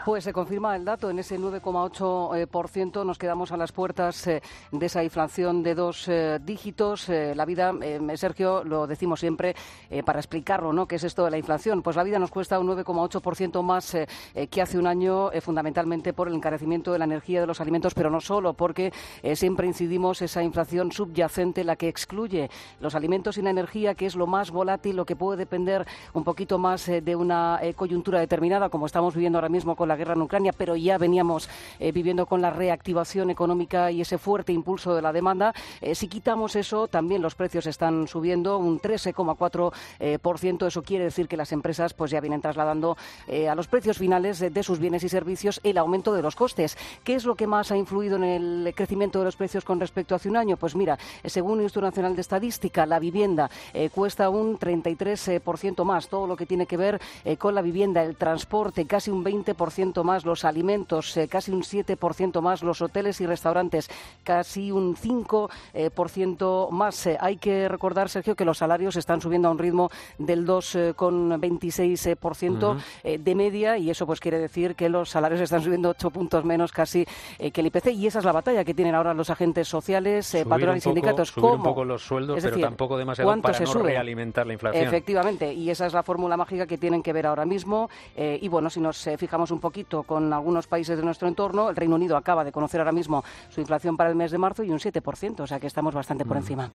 Se confirma la inflación de marzo en el 9,8 %, la más alta desde 1985. Crónica